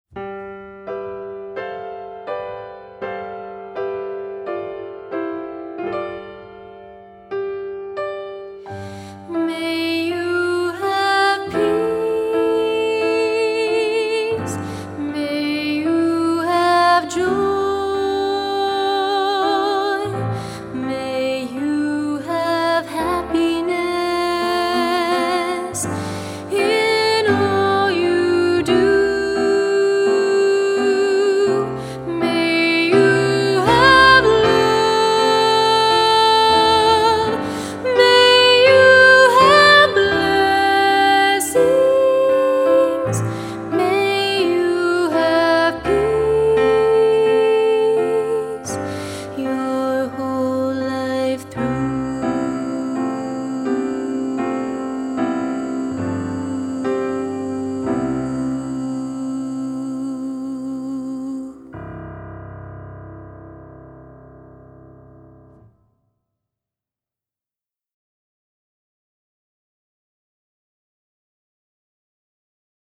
3-part song